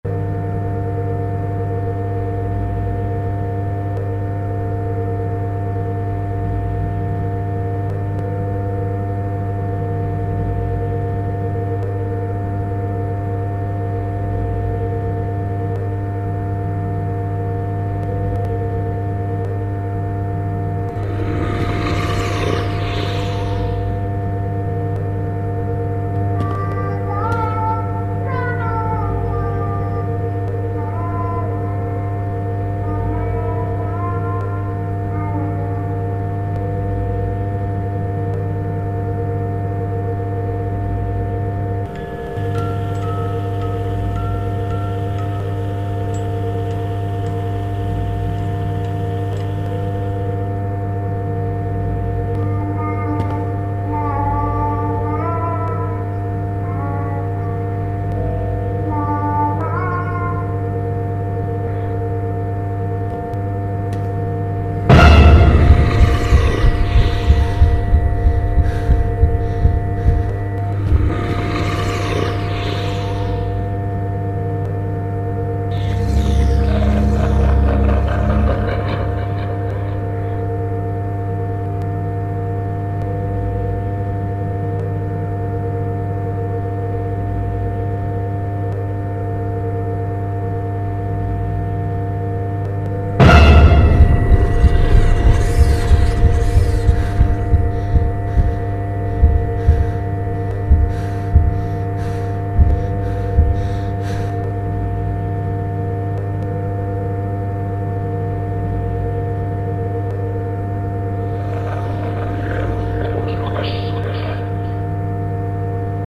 Погрузитесь в тревожную атмосферу Закулисья с нашей коллекцией звуков. Скачивайте или слушайте онлайн жужжание флуоресцентных ламп, отдаленные шаги в пустых коридорах, непонятный шепот и другие аудио-сигналы из разных уровней.